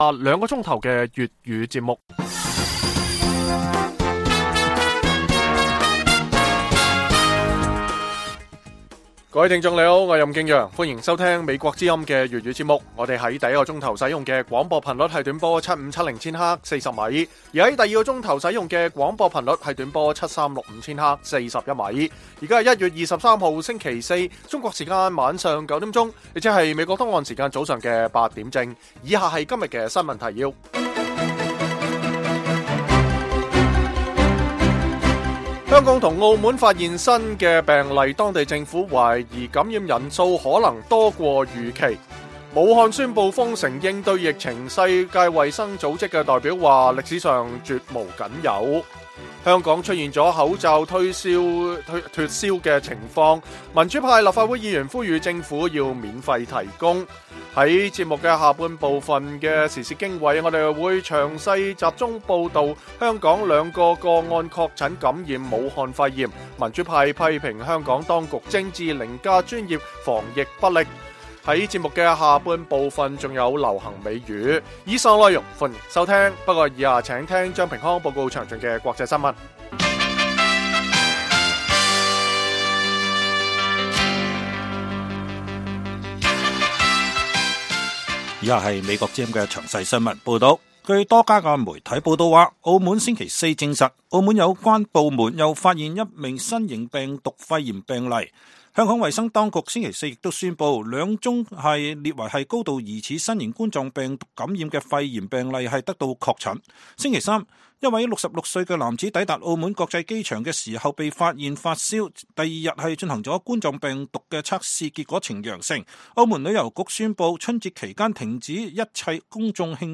北京時間每晚9－10點 (1300-1400 UTC)粵語廣播節目。內容包括國際新聞、時事經緯和英語教學。